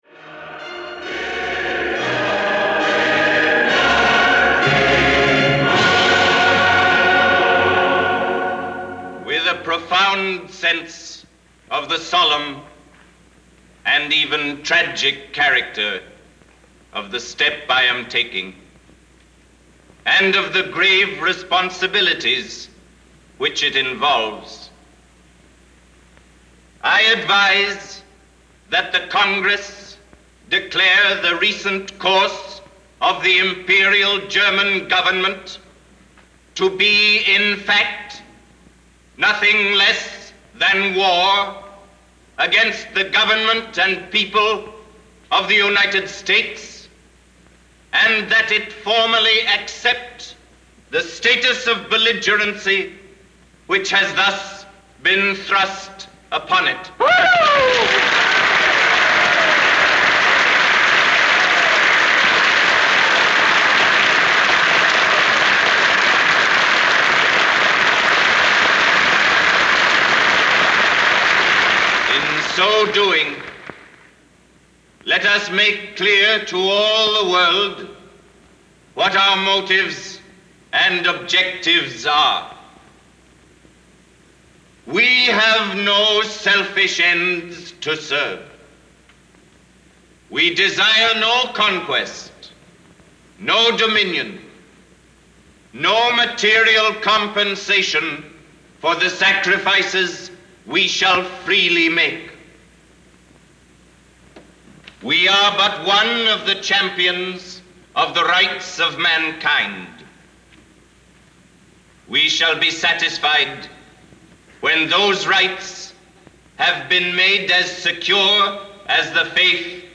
American Rhetoric: Movie Speech
Audio mp3 delivered by Alexander Knox       Audio AR-XE mp3 delivered by Alexander Knox